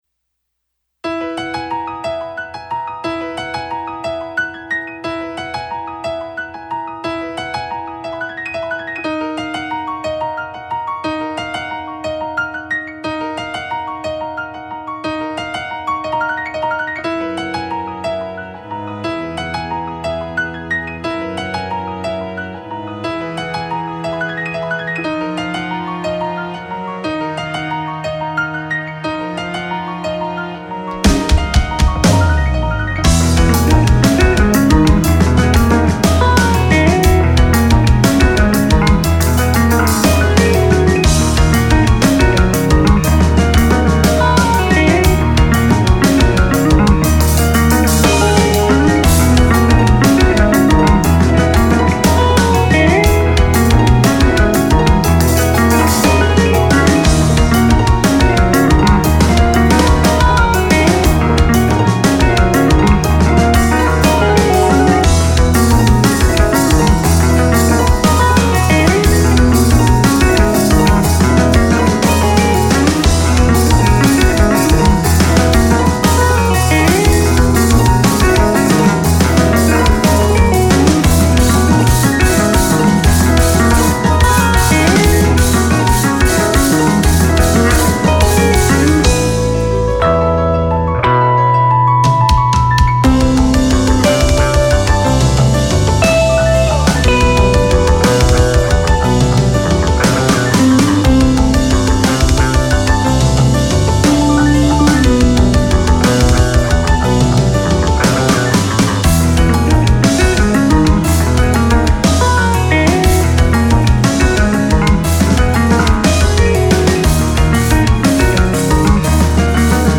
Piano, Bass, Drums, Orchestration
Guitar